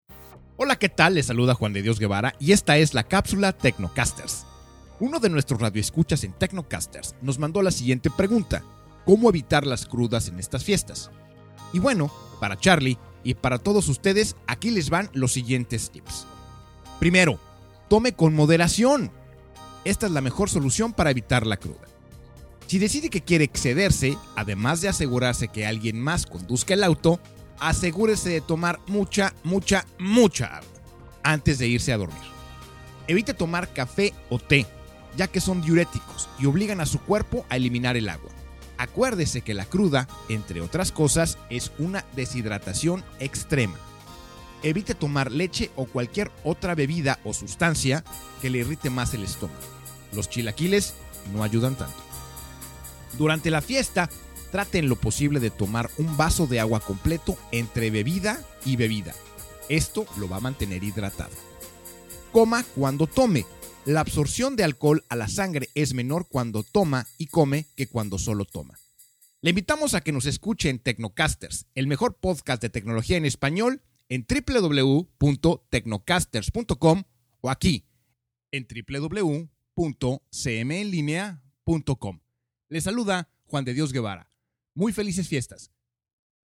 Capsula TecnoCasters - Como evitar la cruda - Capsula para transmision en Radio